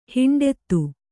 ♪ hiṇḍettu